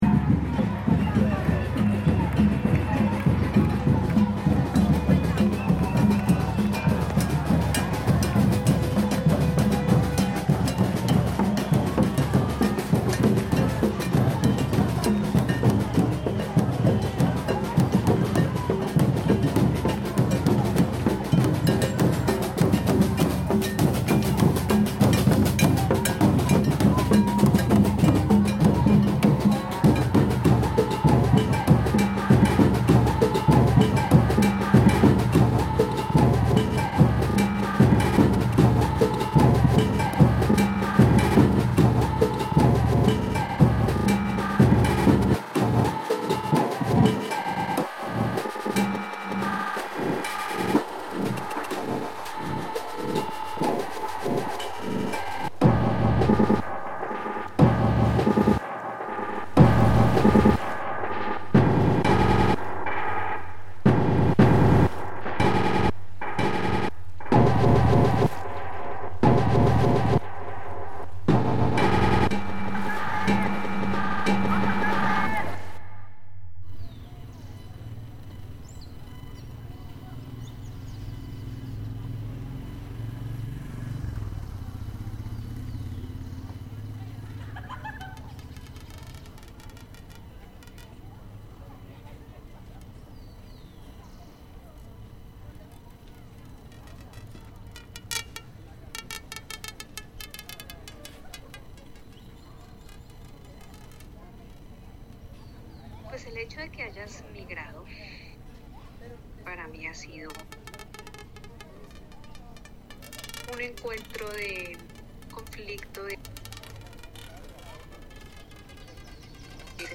Mexico City protest reimagined